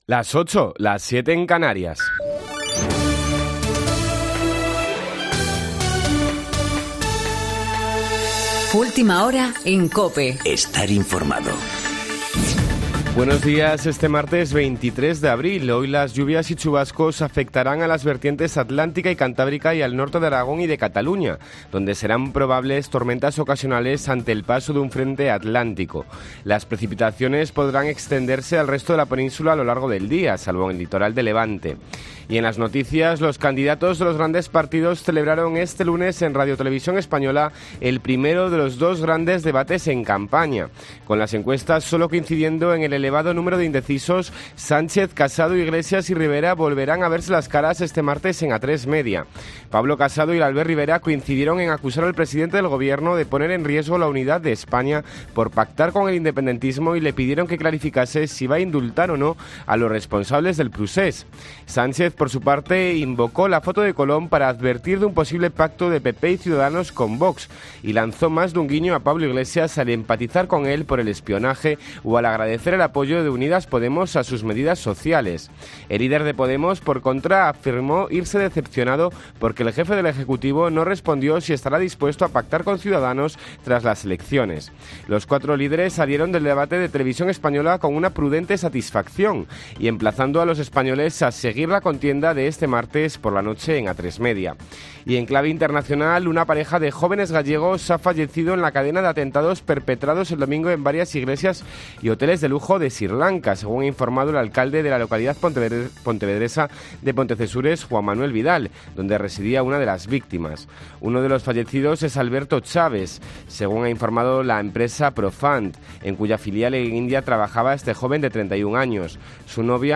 Boletín de noticias COPE del 23 de abril de 2019 a las 08.00 horas